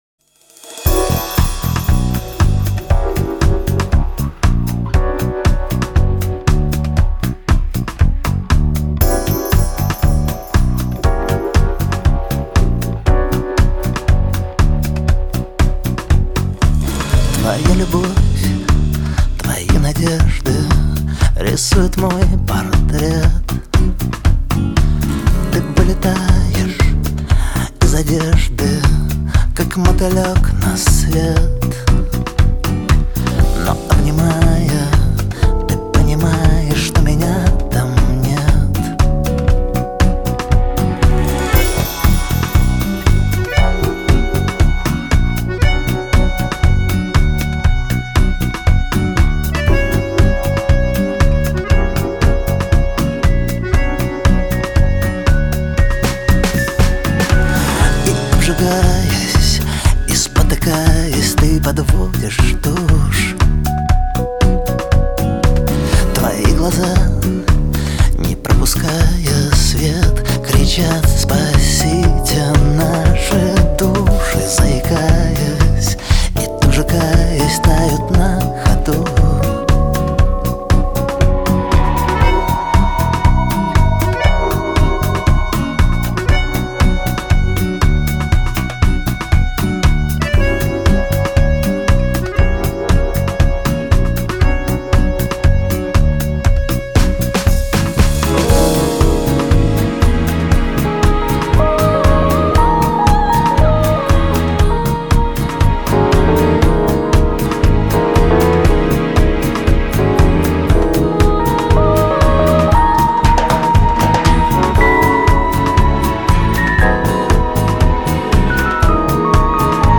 Софт рок Поп рок